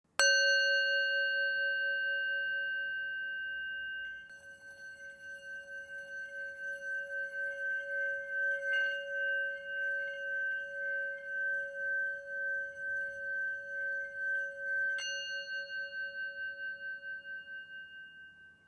Tepaná tibetská mísa Dawa o hmotnosti 313 g a malá palička s kůží.
Zvuk tibetské misy Dawa si můžete poslechnout zde
tibetska_misa_M03.mp3